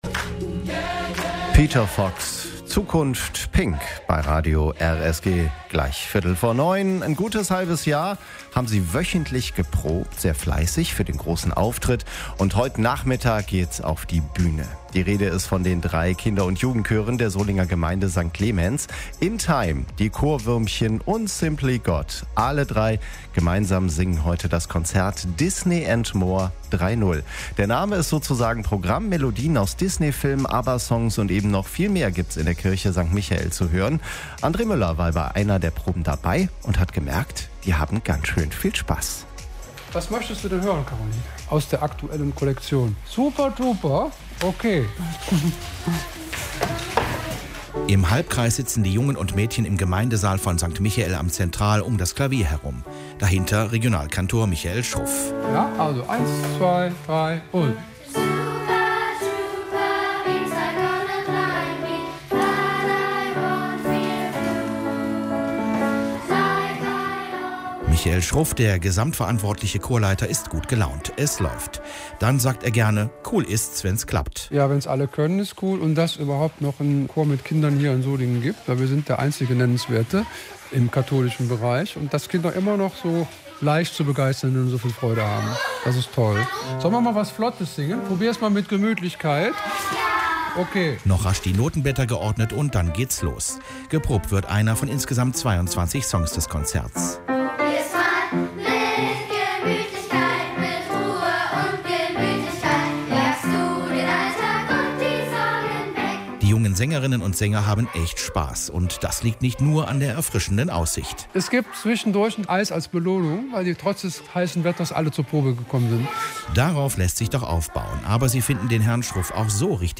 Kinderchöre singen heute Disneysongs und mehr
Wir waren bei einer Probe dabei.